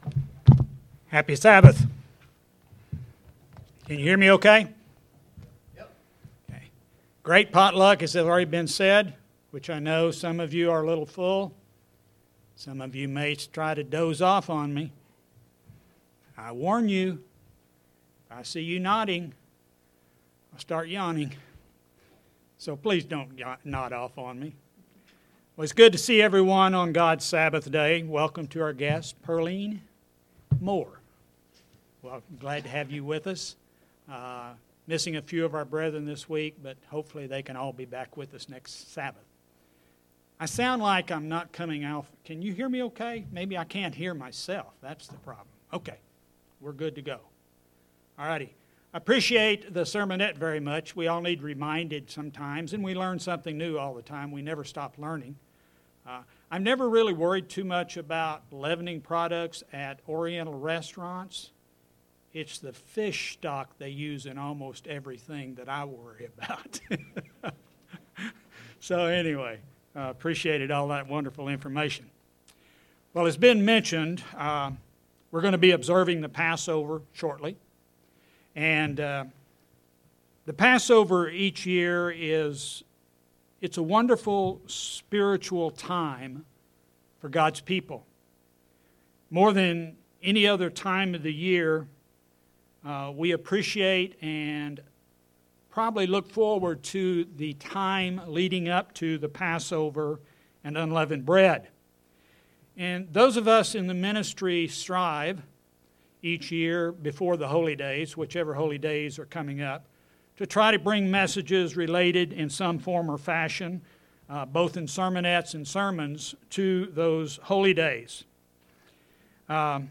This sermon examines the topic of Passover and the relationship it has with fellowship.